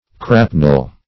Crapnel \Crap"nel\ (kr?p"nel), n. A hook or drag; a grapnel.